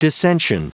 Prononciation du mot dissension en anglais (fichier audio)
Prononciation du mot : dissension